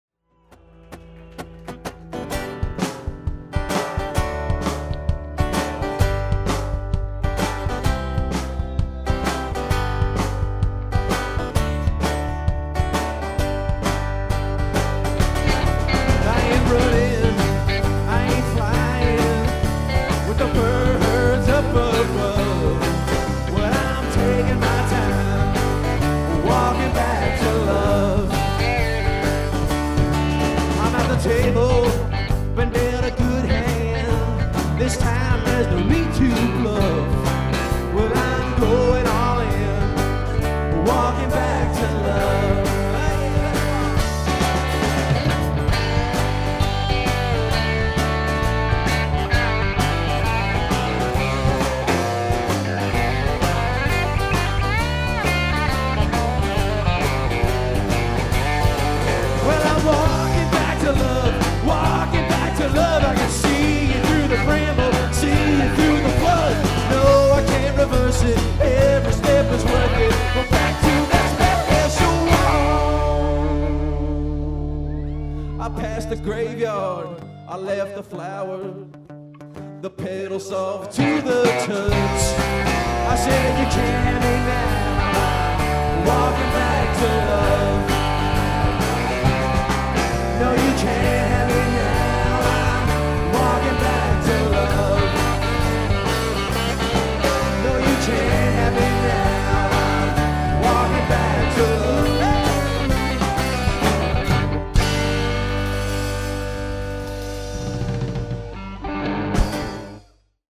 Live at Southpaw Brooklyn NY
Lead Guitar
Bass Guitar